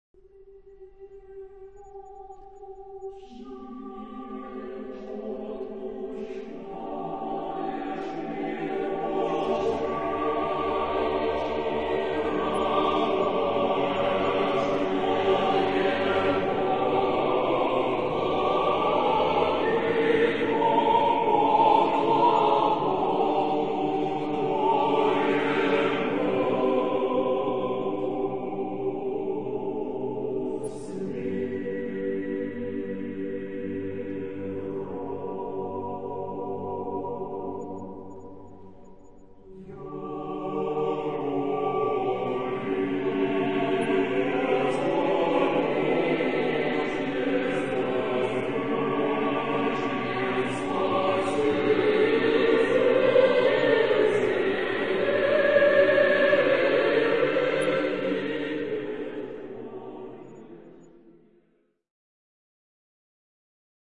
Genre-Style-Forme : Choral ; Orthodoxe ; Sacré